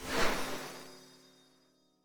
start_ritual.ogg